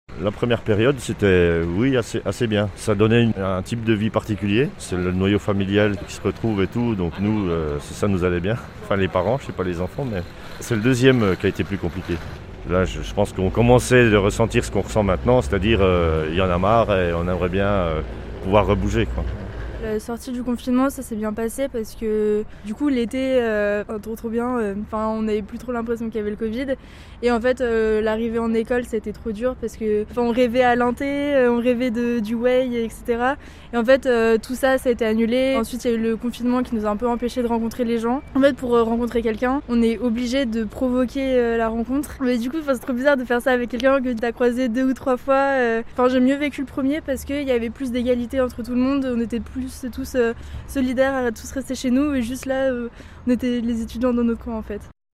Comment avez-vous vécu le premier puis le second confinement ? C’est la question que nous avons posé aux passants à Annonay, en Ardèche, au Péage-de-Roussillon, en Isère et à Valence, dans la Drôme.